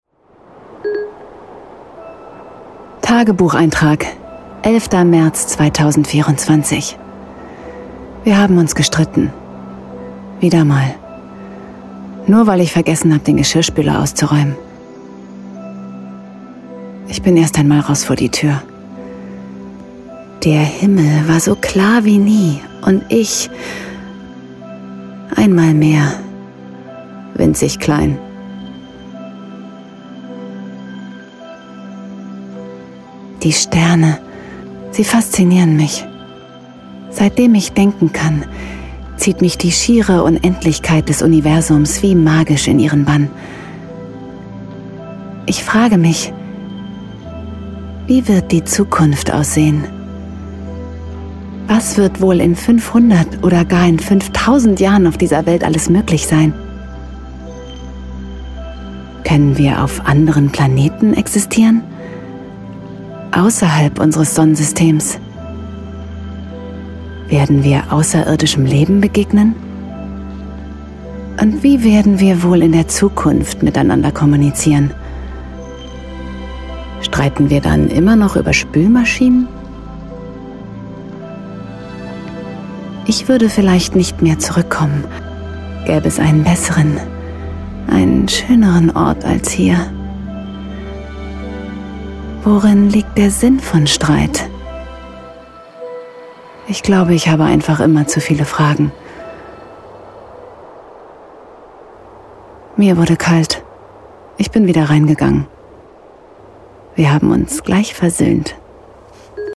Dialekte: Hamburgisch, Norddeutsch
Tagebuch - Erzählung I